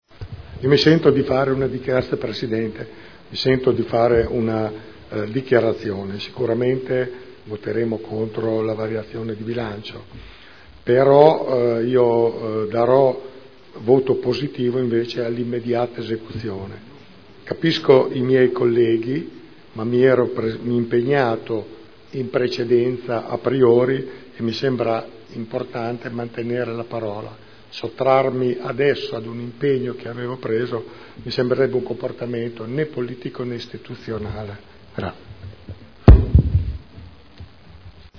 Giancarlo Pellacani — Sito Audio Consiglio Comunale